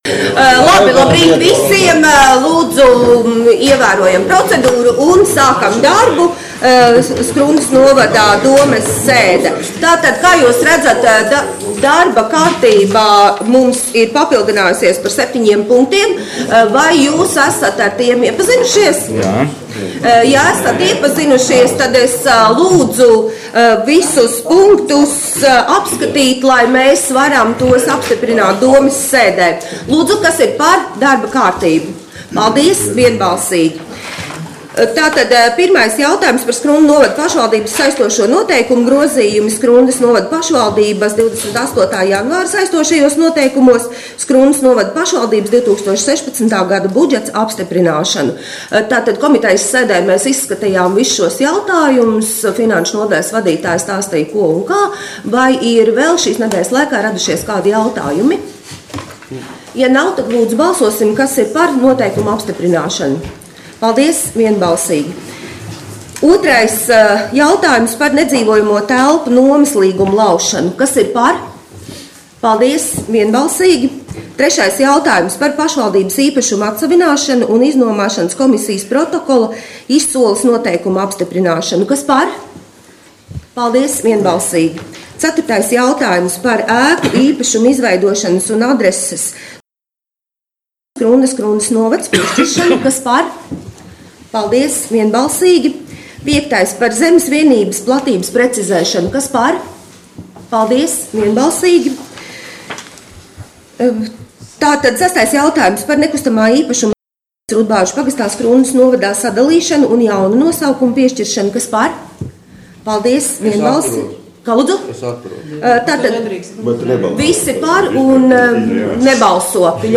Skrundas novada domes 2016. gada 27. oktobra sēde